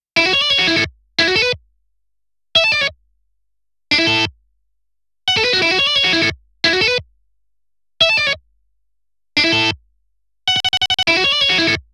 その他素材(エレキギター)試聴